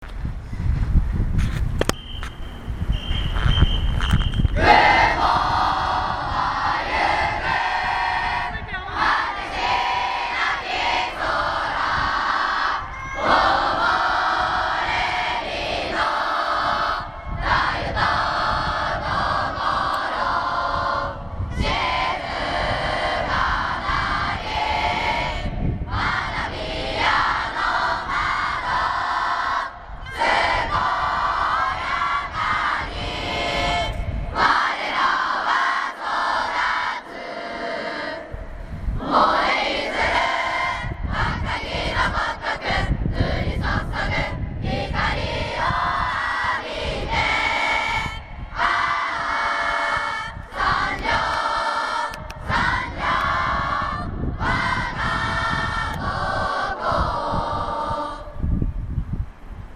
2年生校歌